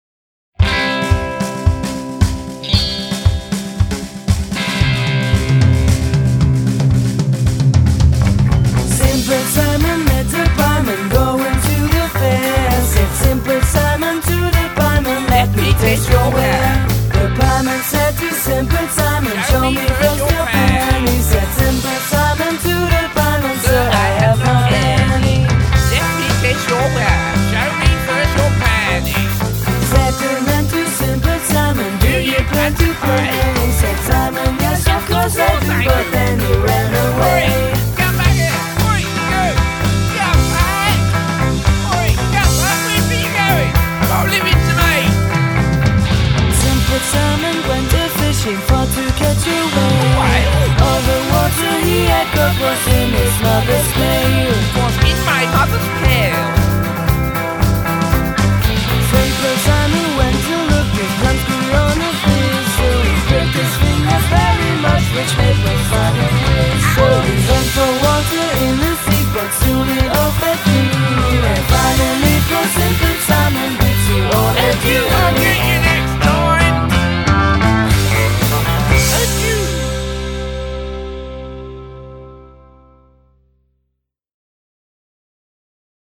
RockFun & Punk